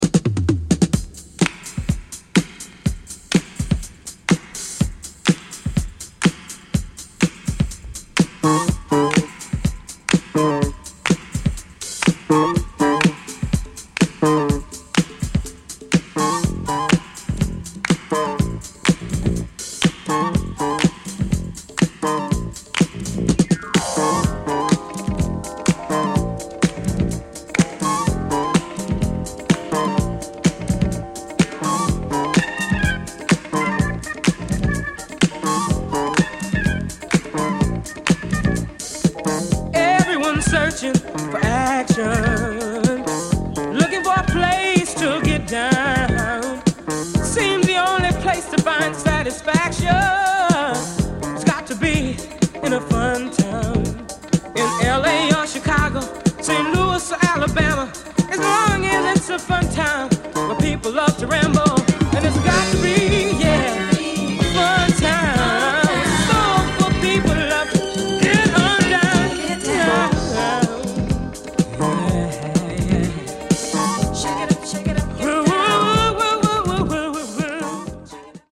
Obscure Pre-Disco